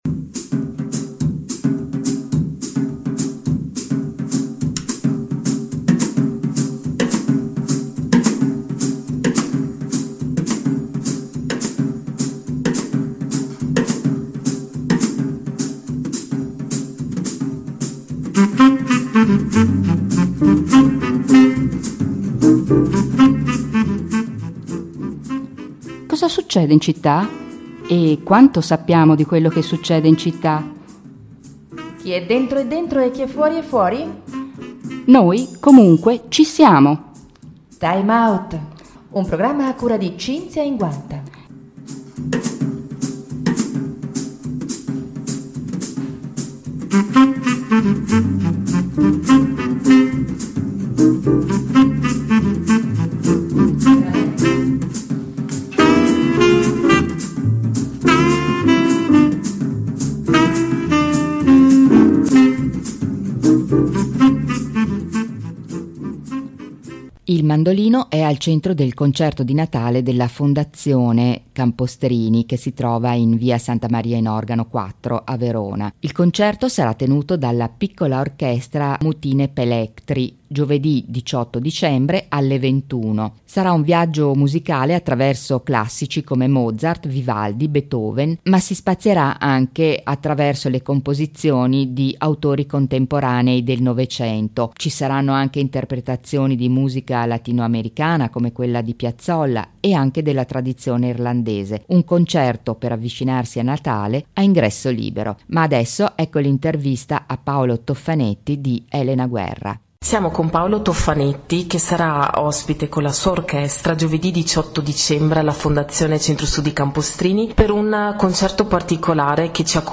Intervista all'interno del programma Time Out di Radio Popolare Verona.